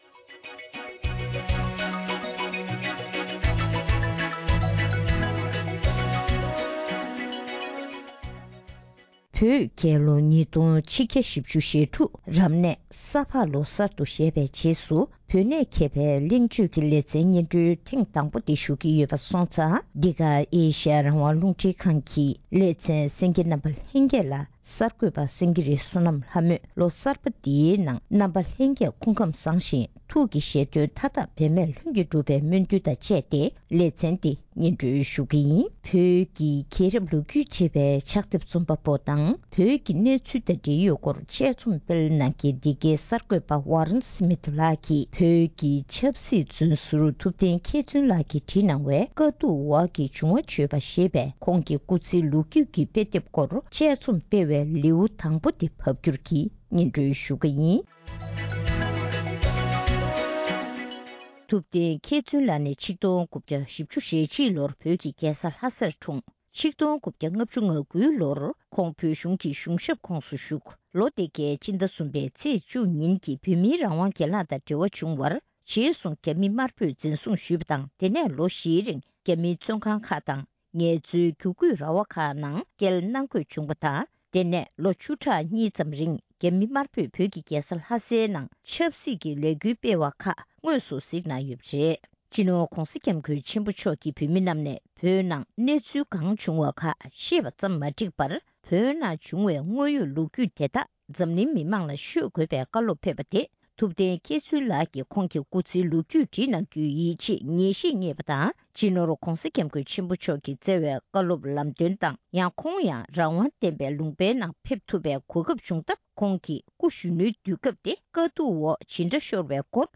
སྒྲ་ལྡན་གསར་འགྱུར།
༄༅། །གནད་དོན་གླེང་མོལ་གྱི་ལས་རིམ་ནང་།